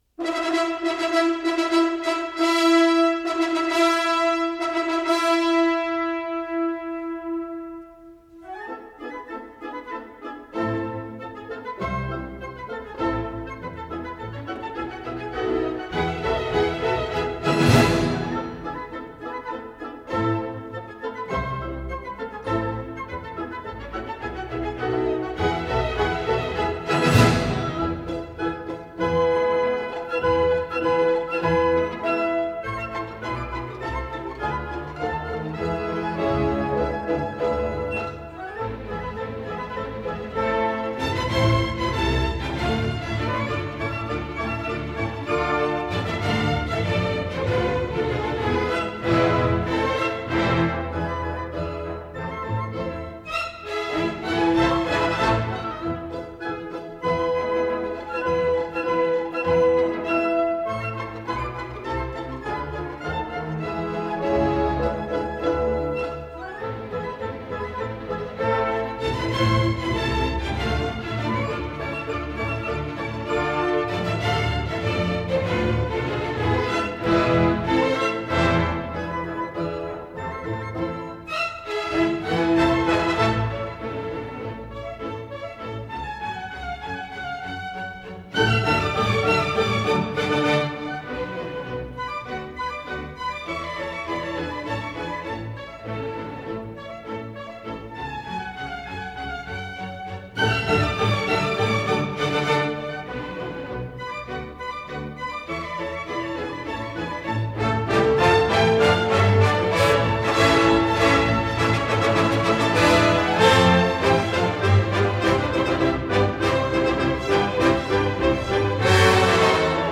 В фильме конечно помощнее звучит...